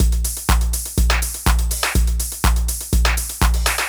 ENE Beat - Mix 1.wav